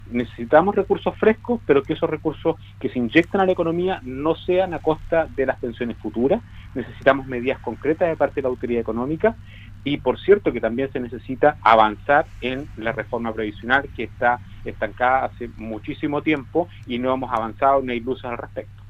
En entrevista con Radio Sago